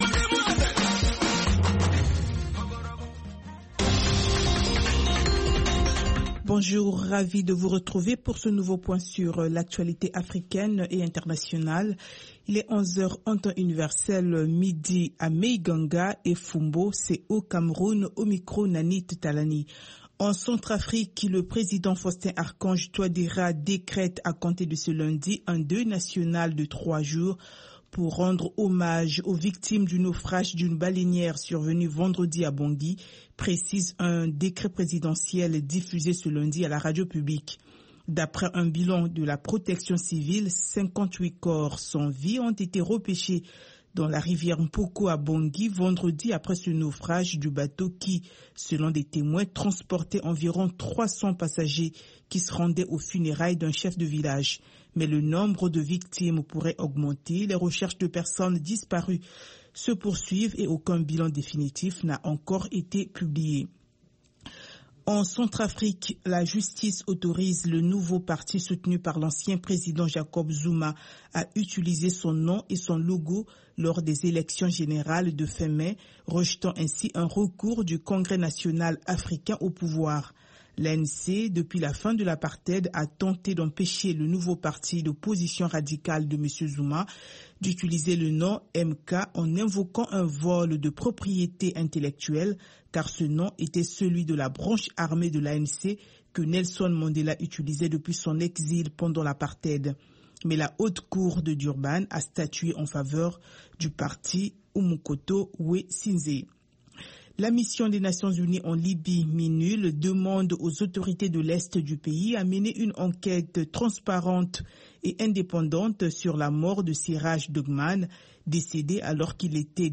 Bulletin d’information de 15 heures
Bienvenu dans ce bulletin d’information de VOA Afrique.